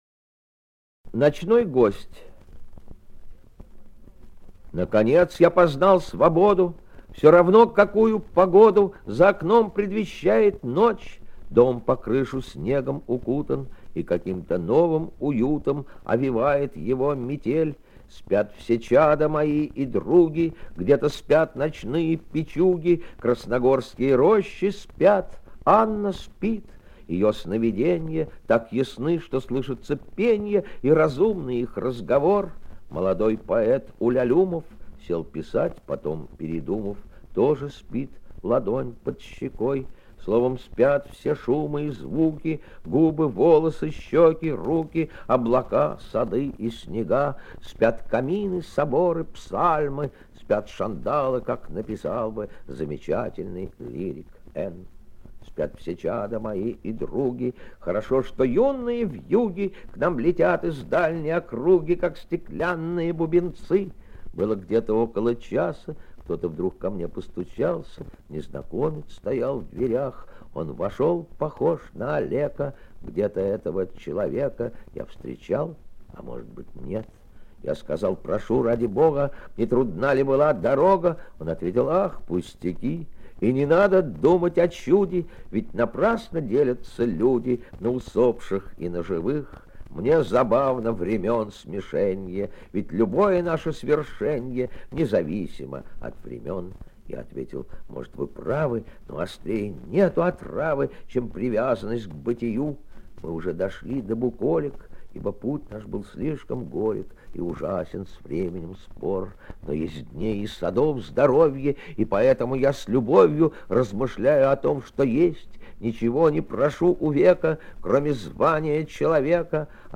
2. «Давид Самойлов – Ночной гость (читает автор)» /
Samoylov-Nochnoy-gost-chitaet-avtor-stih-club-ru.mp3